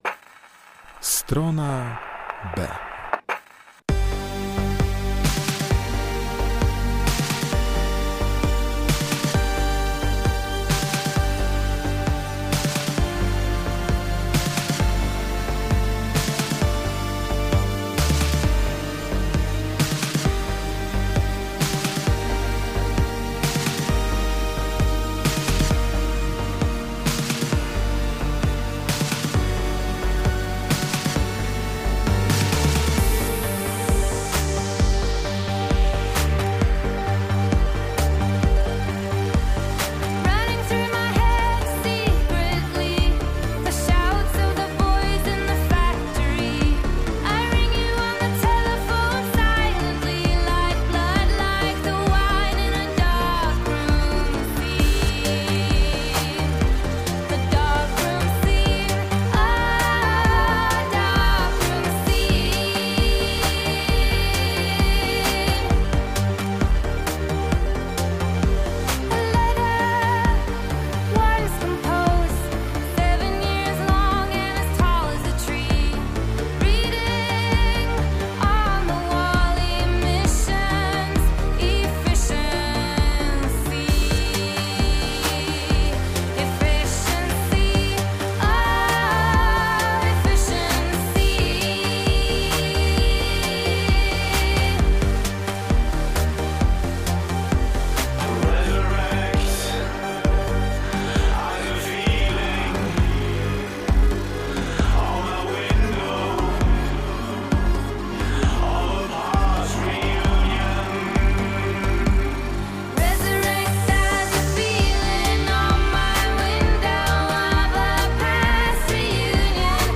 Nasza audycja w elektronicznym stylu dziś w lodowym klimacie.